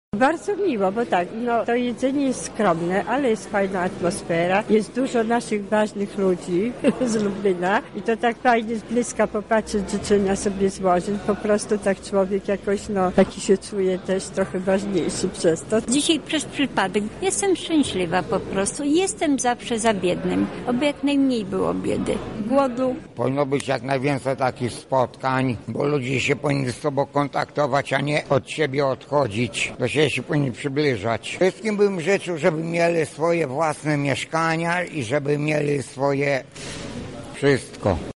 W poczekalni dworca PKS odbyło się spotkanie wigilijne.
Zapytaliśmy uczestników spotkania o ich wrażenia:
Podróżni